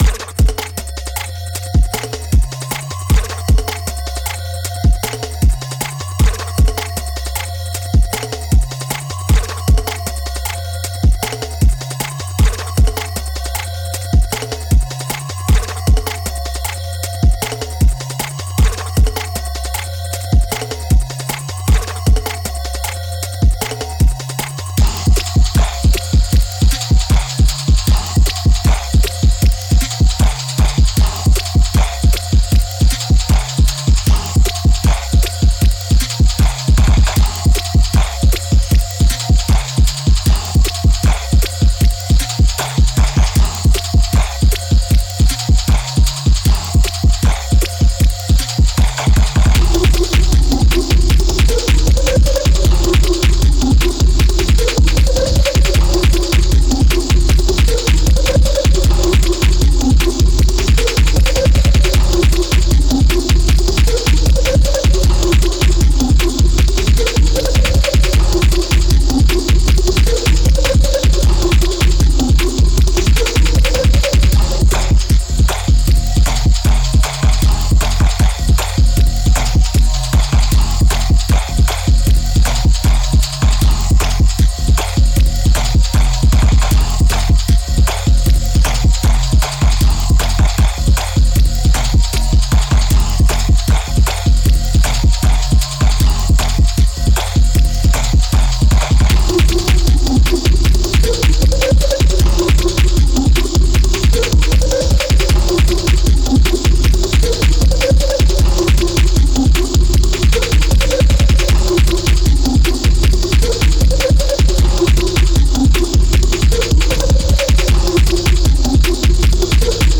come for the sslay SEA tracks & stay for the insightful, warm, & broadening conversation